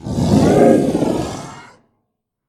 hell_dog1.ogg